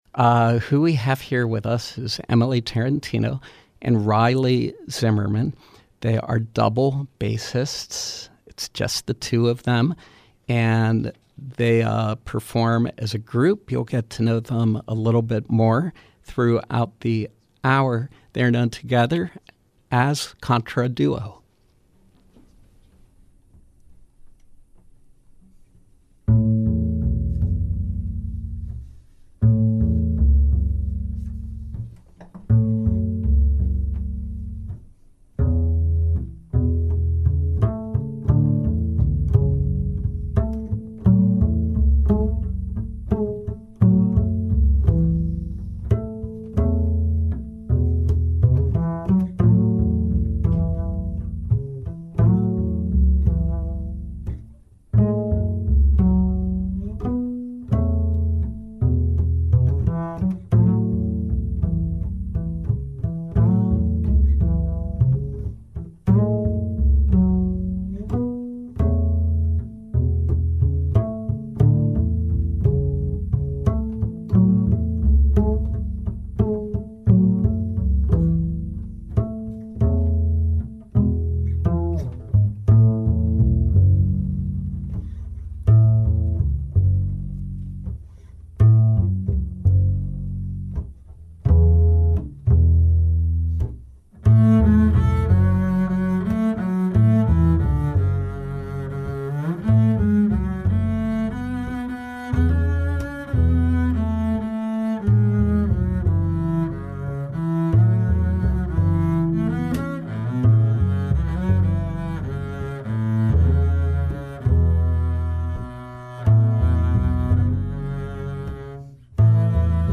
Live music
double bassists